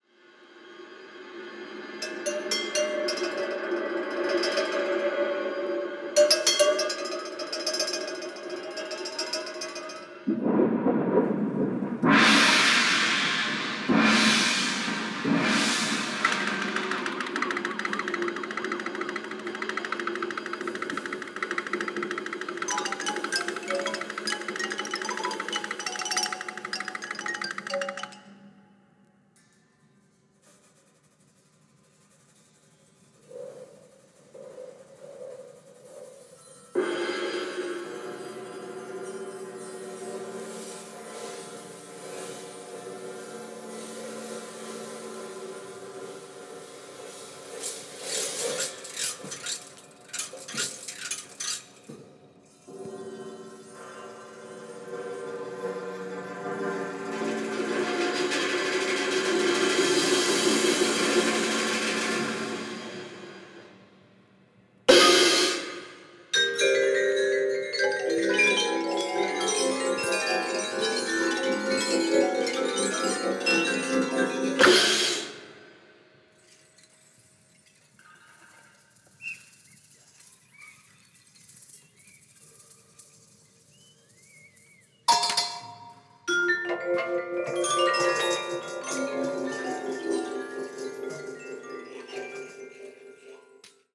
パーカッション
間を大切にする和の精神にも通じるアルバムです。